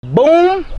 boom_vspiX8Y.mp3